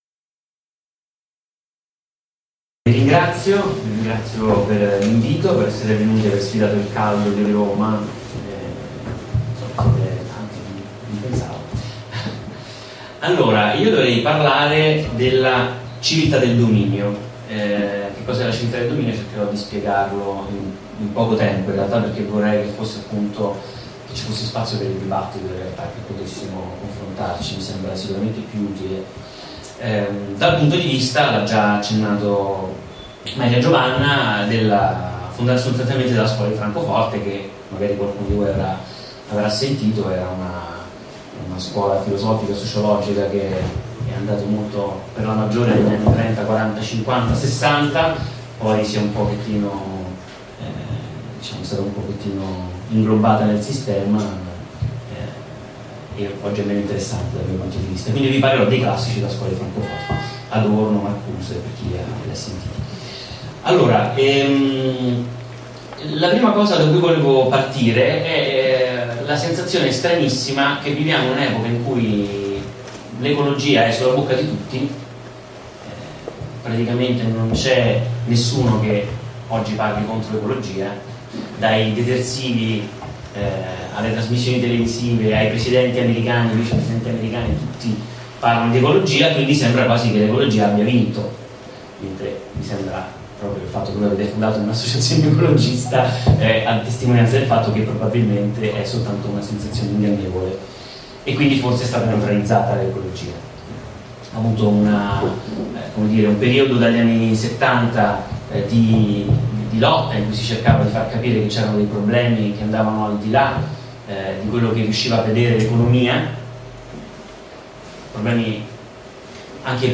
Roma, 5 luglio 2013 presso il Circolo KO in Via degli Ernici.